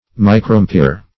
Search Result for " microampere" : The Collaborative International Dictionary of English v.0.48: Microampere \Mi`cro*am`p[`e]re"\, n. [Micr- + amp[`e]re.] (Elec.) One of the smaller measures of electrical currents; the millionth part of one amp[`e]re.